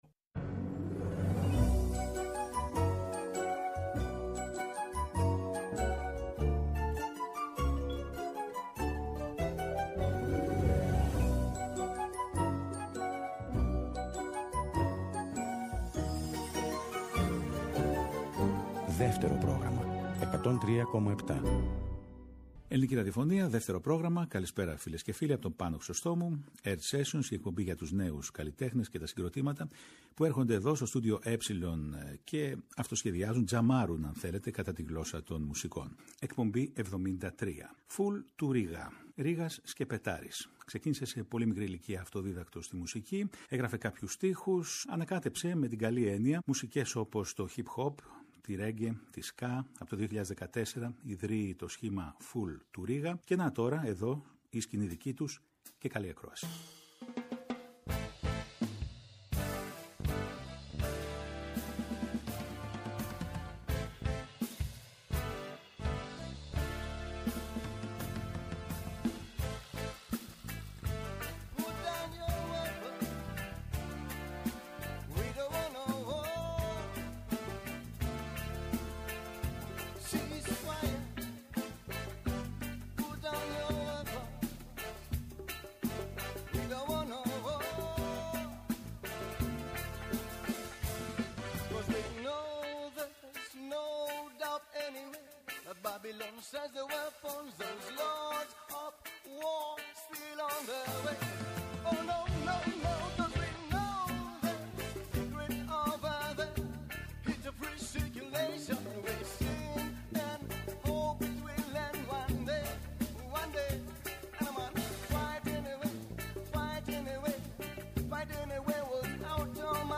καλλιτέχνες και τα συγκροτήματα του ηλεκτρικού ήχου